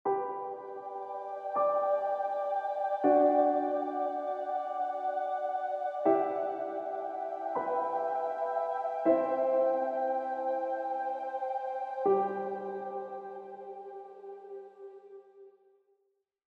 Efeito de acordes melódicos